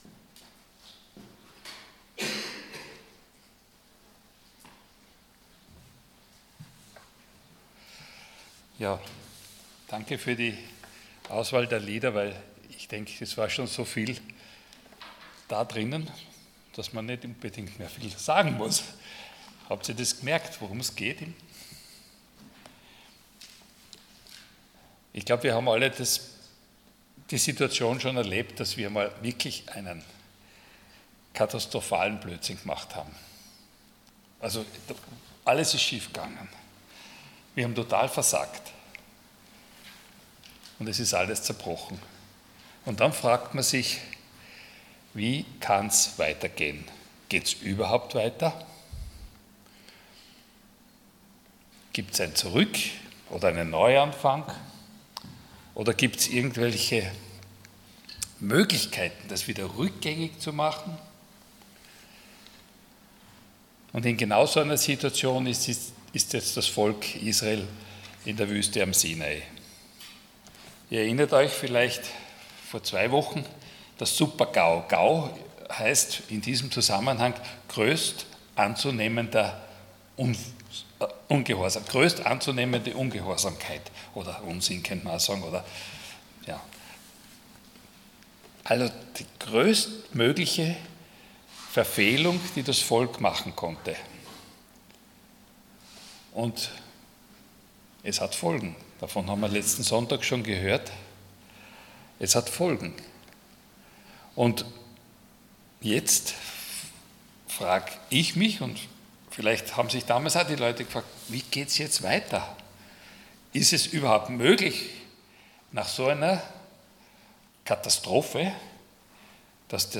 Unterwegs zum Ziel Passage: 2. Mose 34 Dienstart: Sonntag Morgen Nach dem Super-GAU, kann es weitergehen?